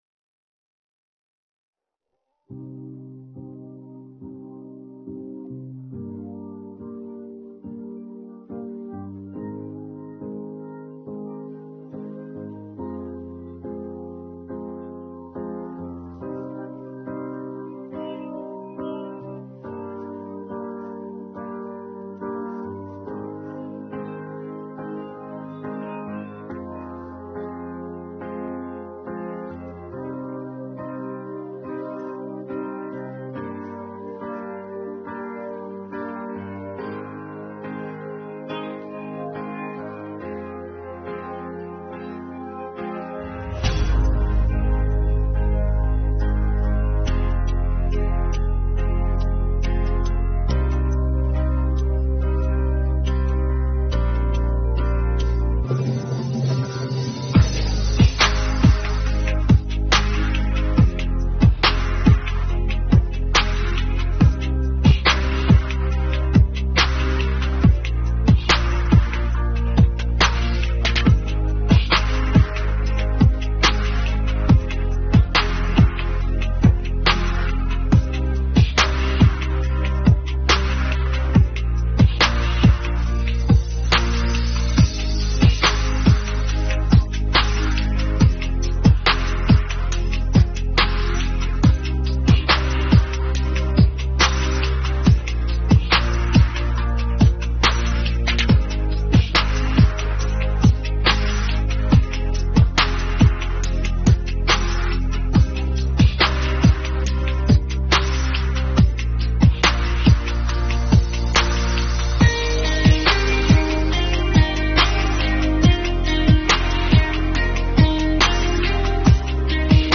Українські хіти караоке